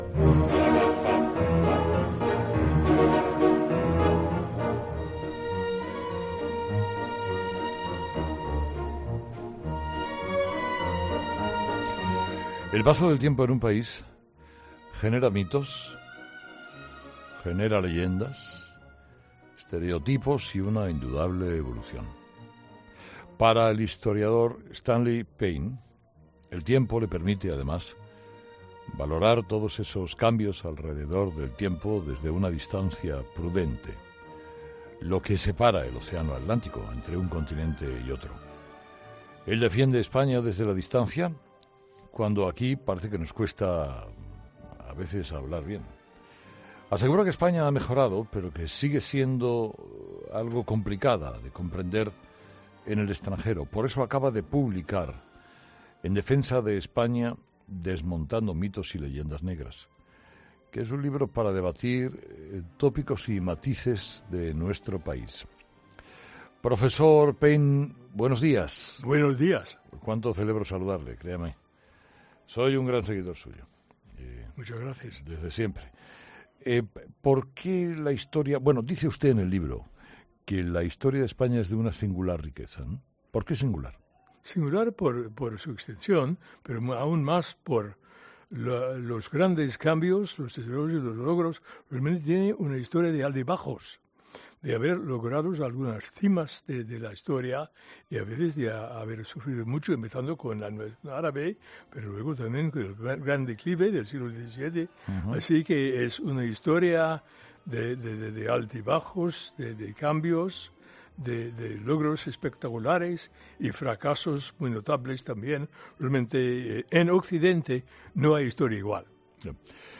Escucha la entrevista a Stanley G. Payne en Herrera en COPE